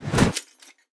ui_interface_263.wav